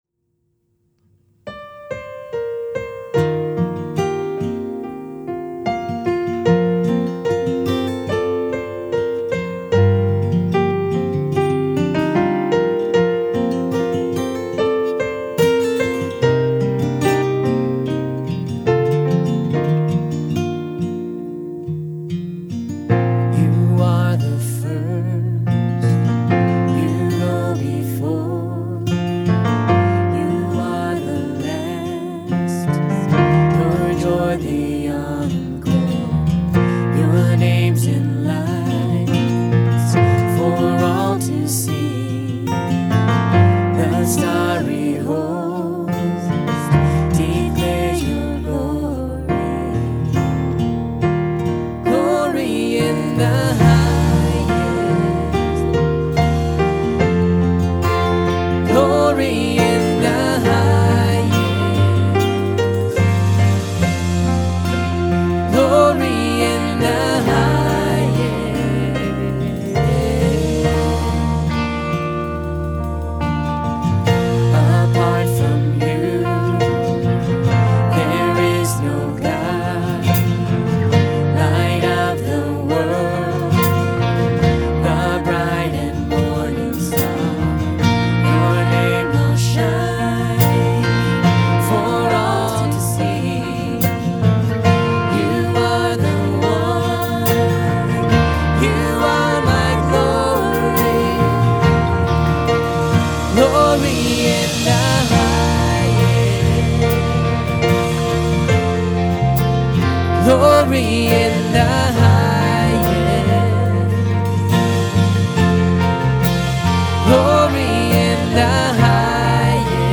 This song was played as part of the opening worship on Sunday, January 12, 2014.